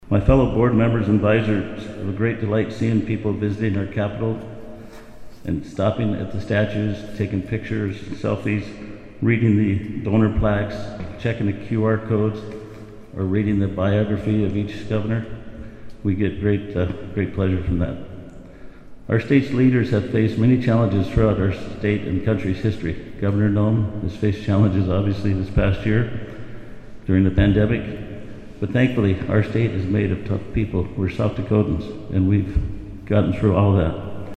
Bronze statues of South Dakota Govs. Charles Sheldon (2), Coe Crawford (6) and Carl Gunderson (11) were unveiled at the State Capitol in Pierre this morning (June 11).